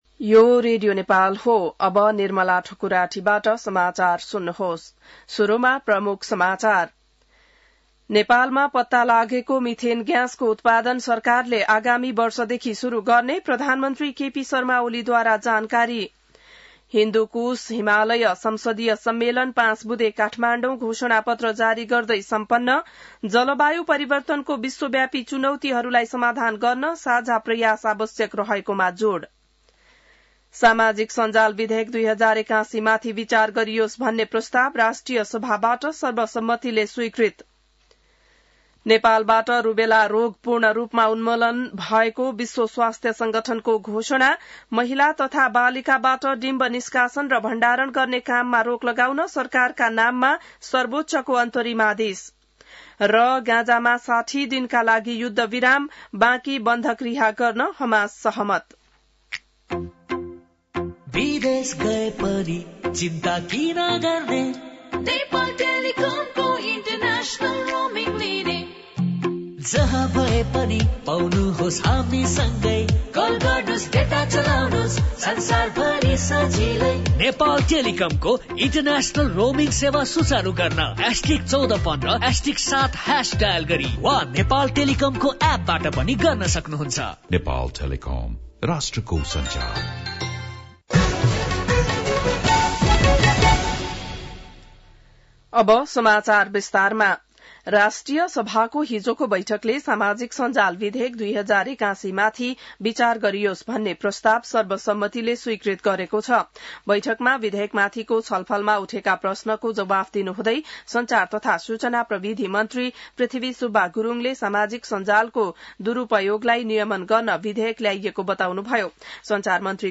बिहान ७ बजेको नेपाली समाचार : ४ भदौ , २०८२